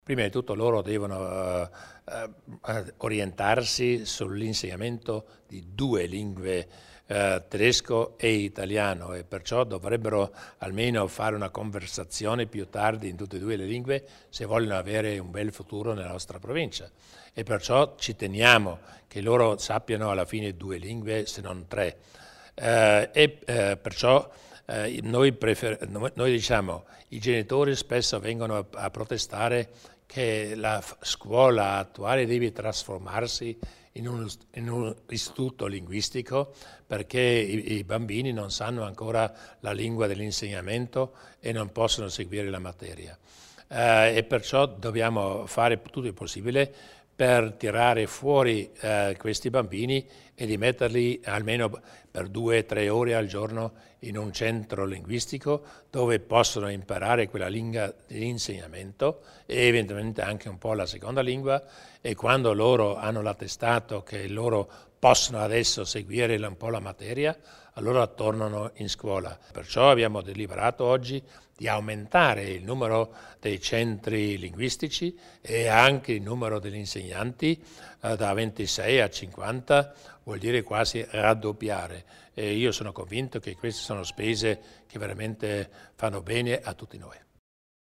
Il Presidente Durnwalder spiega i progetti in tema di apprendimento linguistico